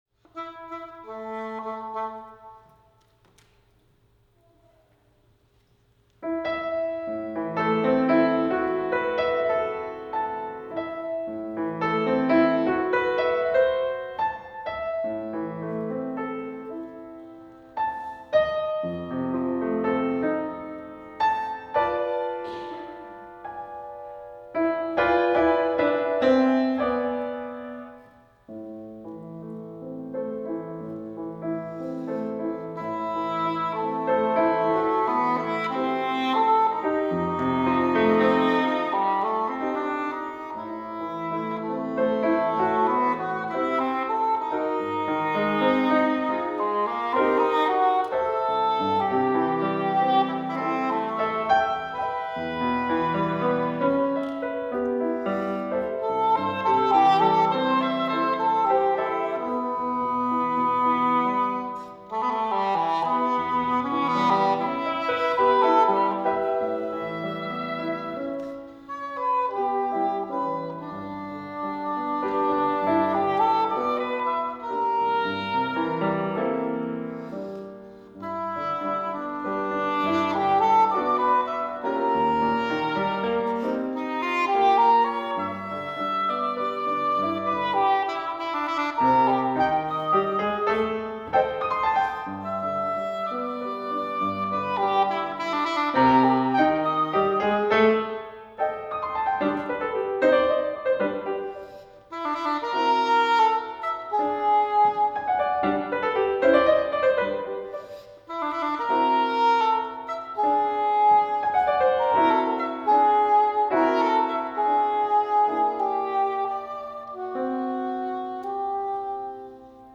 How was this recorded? Performance Recordings